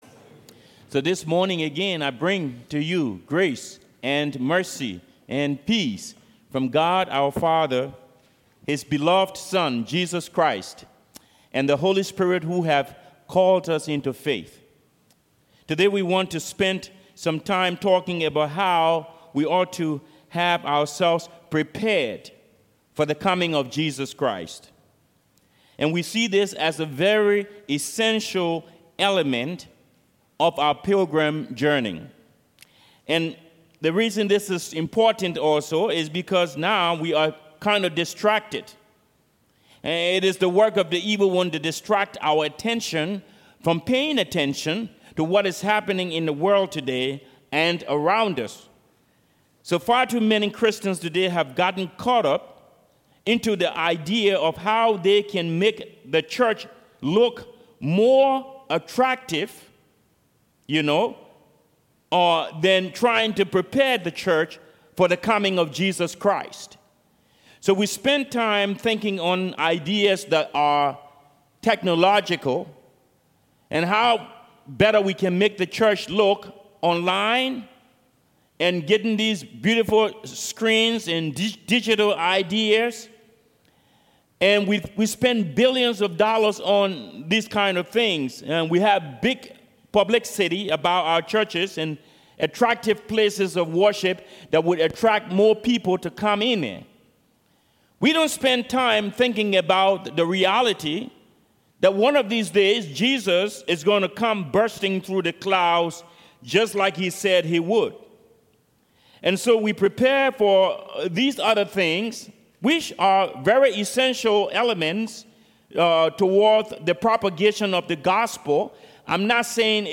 Service Type: Sermons